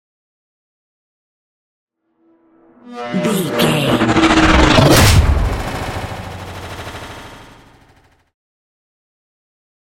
Sci fi whoosh to hit big
Sound Effects
Atonal
dark
futuristic
intense
tension
woosh to hit